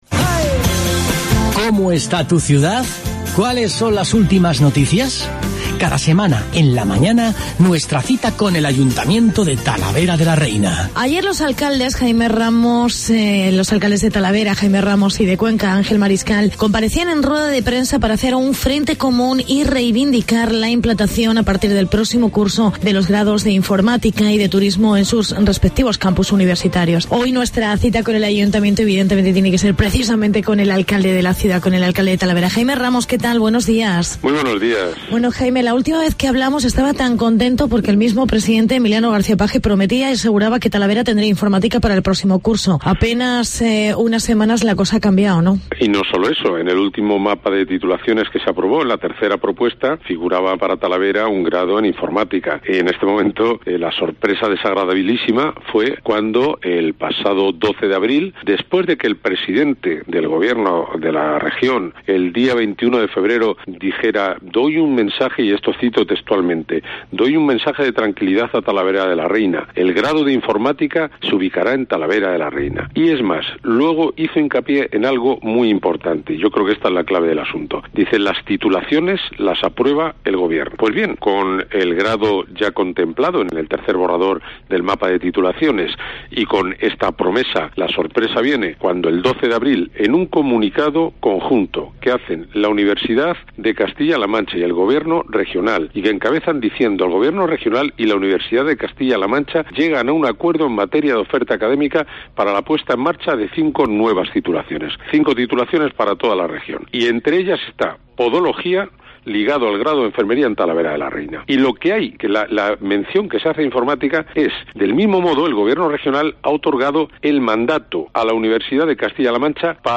Entrevista con Jaime Ramos